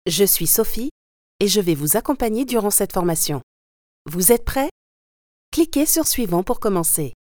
Ma Voix est médium , modulable dans les aigus et graves.
französisch
Sprechprobe: eLearning (Muttersprache):
I am a French Female Voice Talent ,I have a home studio and can therefore deliver my work as soon as possible.